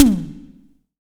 Index of /90_sSampleCDs/Best Service - Real Mega Drums VOL-1/Partition G/SDS V TOM ST